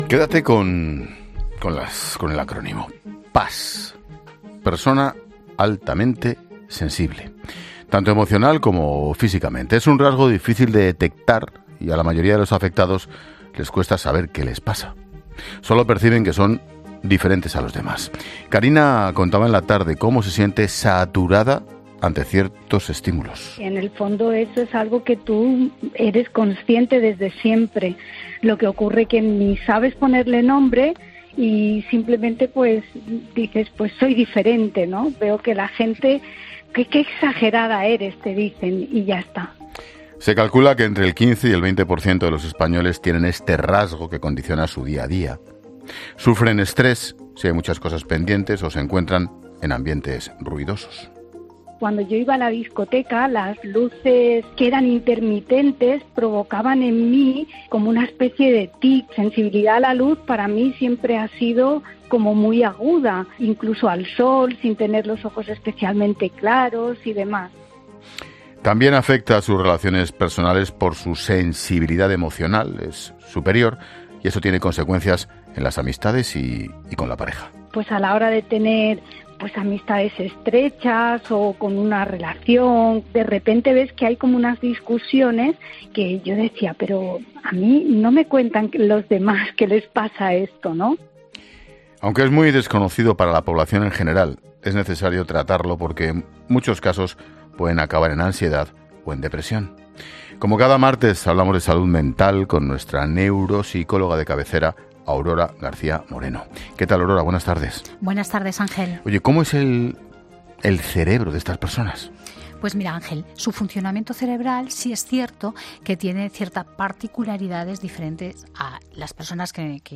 La neuropsicóloga explica cómo afecta ser altamente sensible al ámbito social y laboral, a las relaciones con otras personas: “Muchas veces son incomprendidos.